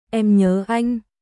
Em nhớ anhエム ニョー アインあなた（男）が恋しい